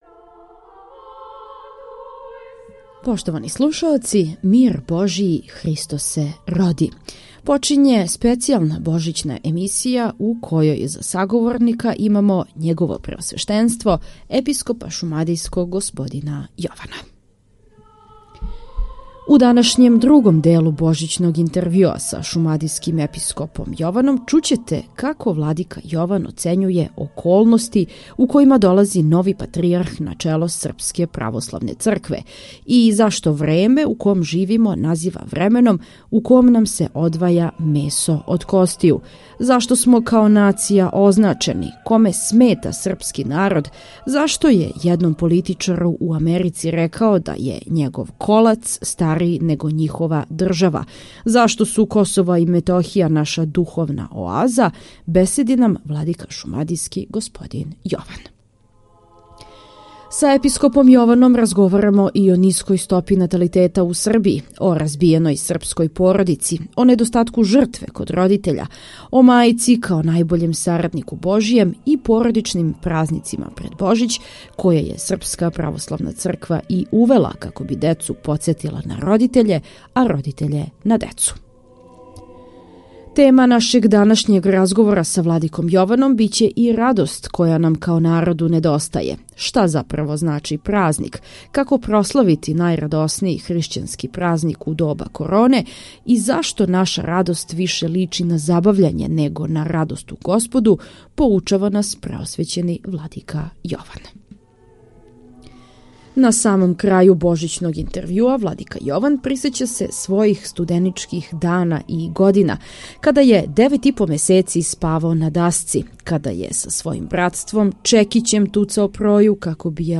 У данашњем, другом делу Божићног интервјуа са Његовим Преосвештенством Епископом Шумадијским Господином Јованом чућете како владика Јован оцењује околности у којима нови патријарх долази на чело Српске Православне Цркве и зашто време у ком живимо назива временом у ком на...